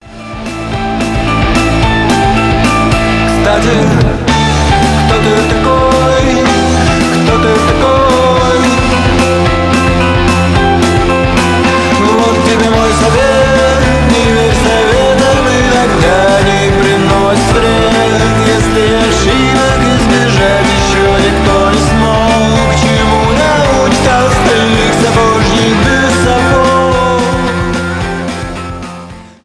рок , indie rock